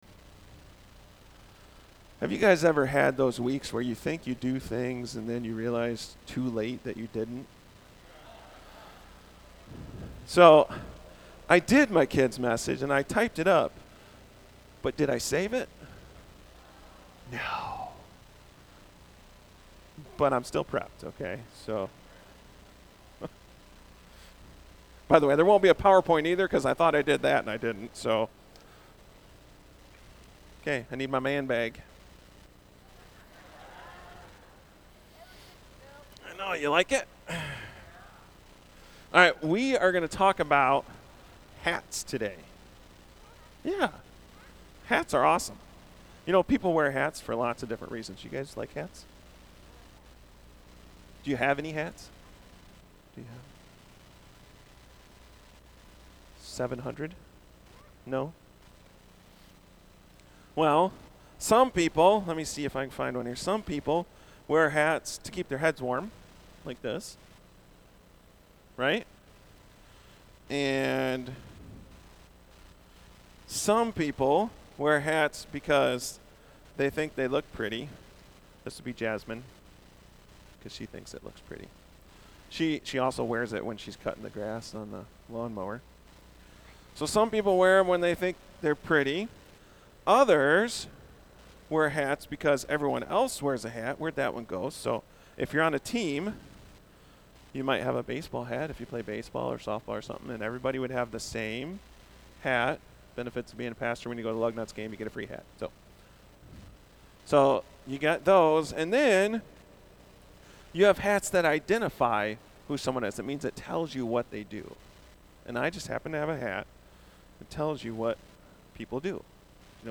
A message from the series "Easter Season." So many of us struggle with knowing and accepting what God is doing in our lives right now.